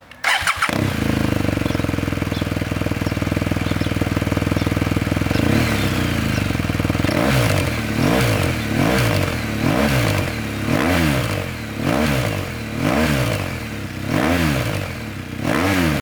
Exhaust System BMW R1200GS : BODIS EXHAUST
PENTA-TEC with dB-Killer.mp3